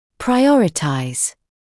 [praɪ’ɔrɪtaɪz][прай’оритайз]располагать в приоритетном порядке; определять приоритет; отдавать предпочтение